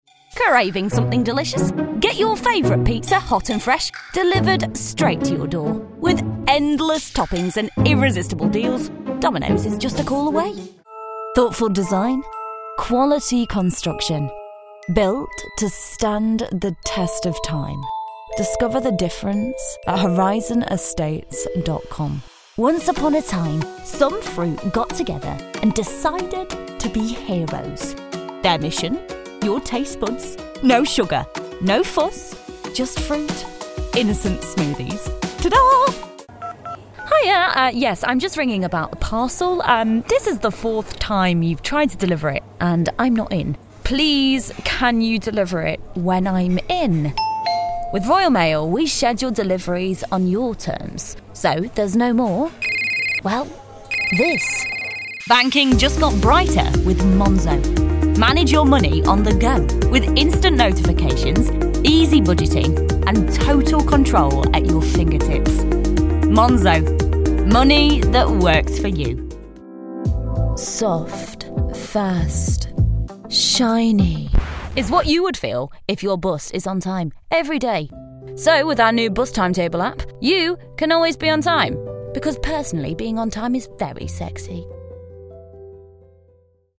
Hire Mafra Lisbon Voice Over Artists
Male 30s , 40s , 50s , 60s , 60s + American English (Native) , Canadian English (Native) , French Canadian (Native) , British English (Native) , Latin American Spanish Approachable , Assured , Authoritative , Character , Confident , Corporate , Deep , Energetic , Engaging , Friendly , Gravitas , Reassuring , Versatile , Warm